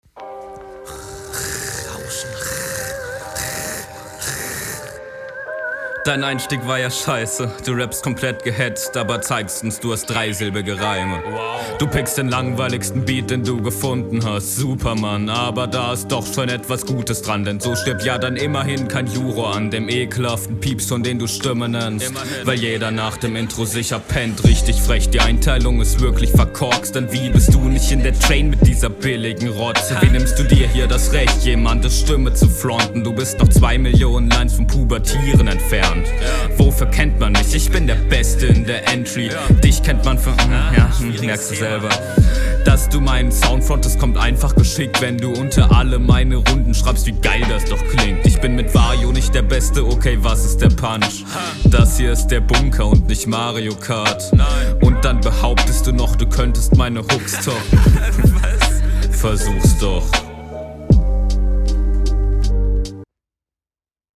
Die Mische ist stark. doubles feier ich, aber ein kleines wenig zu laut.